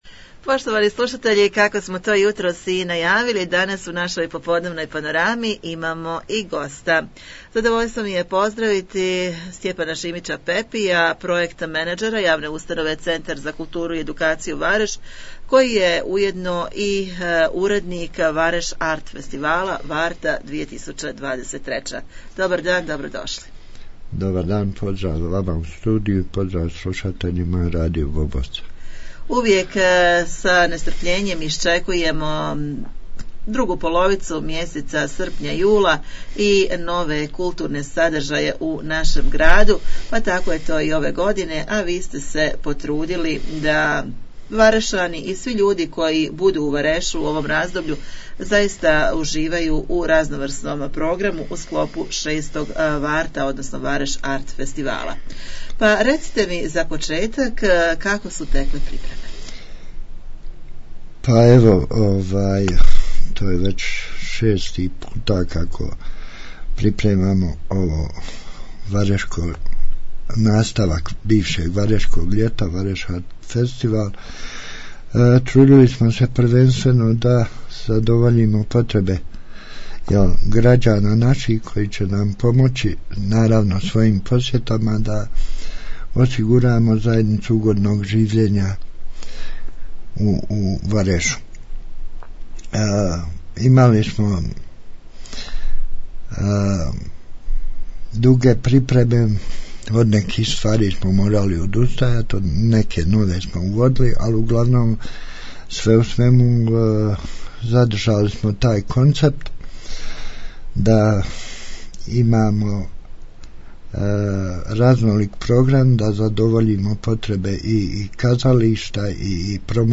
U studiju smo razgovarali